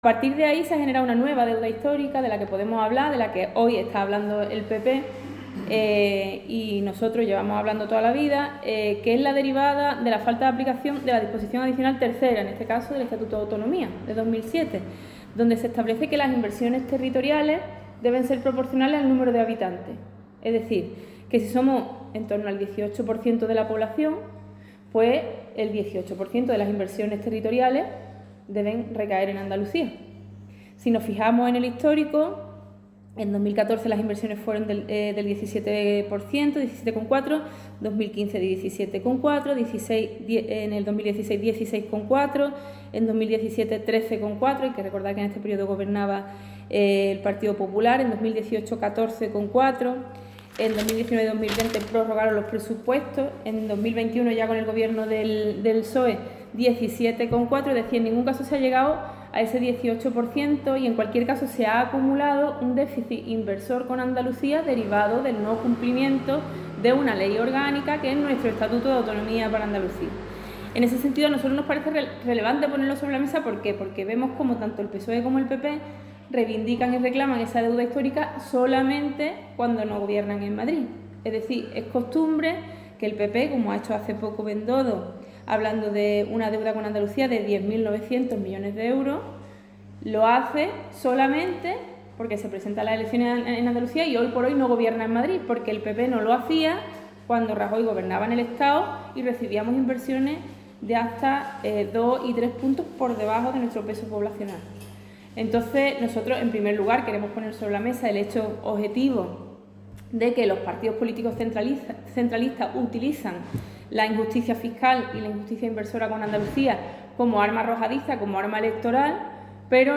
“Tanto el PSOE como el PP reivindican y reclaman la deuda histórica solo cuando no gobiernan en Madrid”, ha recordado esta mañana en rueda de prensa Teresa Rodríguez, candidata a la Presidencia de la Junta de Andalucía por Adelante Andalucía.